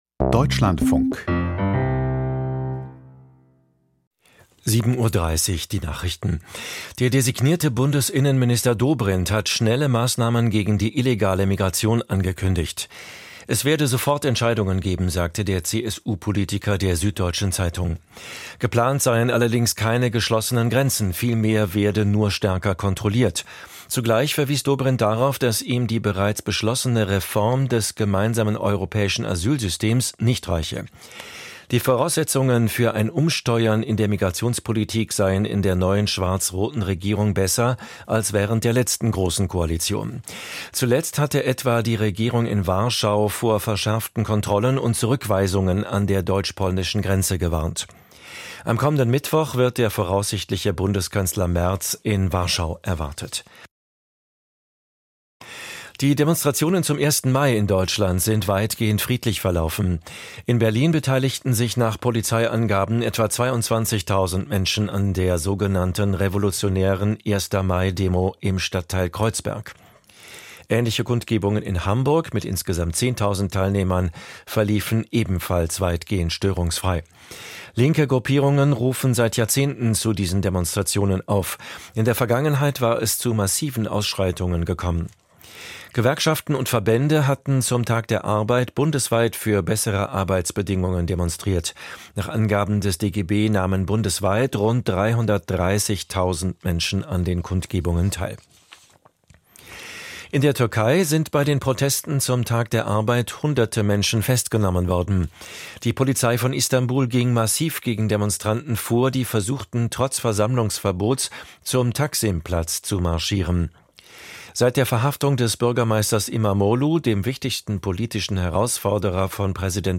Die Deutschlandfunk-Nachrichten vom 02.05.2025, 07:30 Uhr